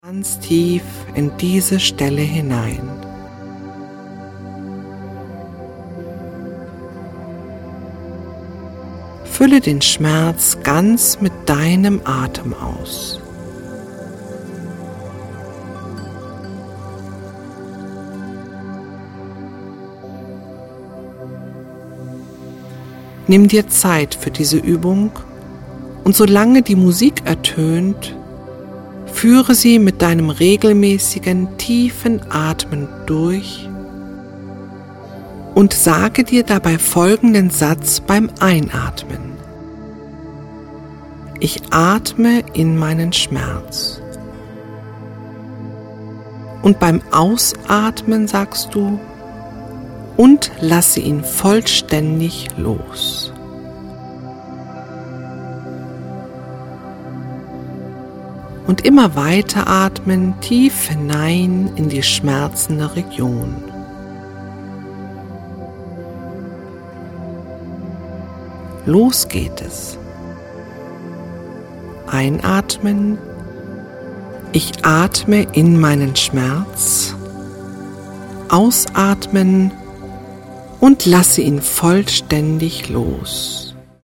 Entspannung & Atmungsstechnik & 432 Hz Musik
weibliche Stimme